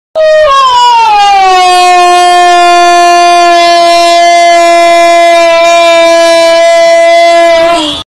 Big Floppa Yelling